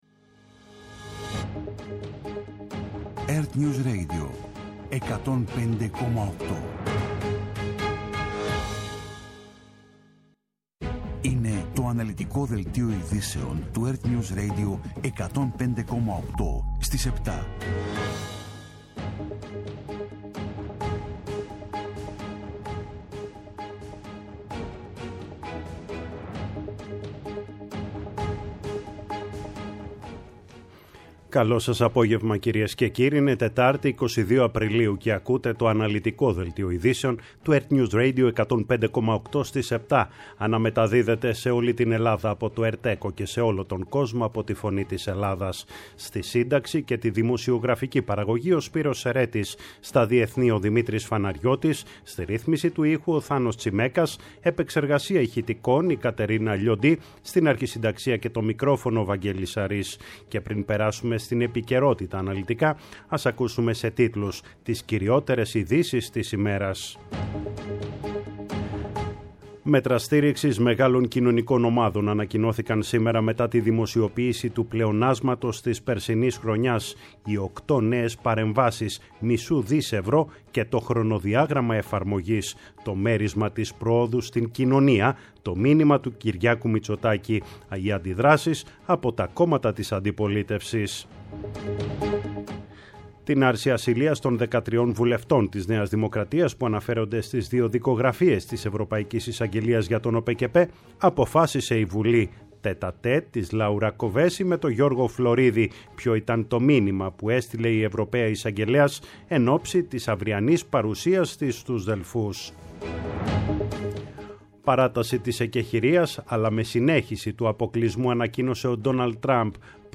Το αναλυτικό ενημερωτικό μαγκαζίνο στις 19:00
Με το μεγαλύτερο δίκτυο ανταποκριτών σε όλη τη χώρα, αναλυτικά ρεπορτάζ και συνεντεύξεις επικαιρότητας.